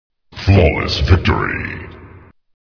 Здесь вы найдёте узнаваемые крики героев, эффекты ударов, культовые фразы и музыку из игры.